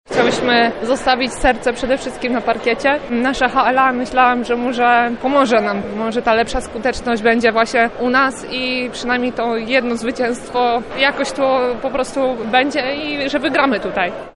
Tłumaczy lubelska koszykarka